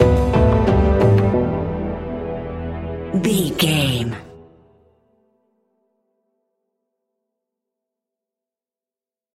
Aeolian/Minor
ominous
dark
eerie
percussion
synthesizer
horror music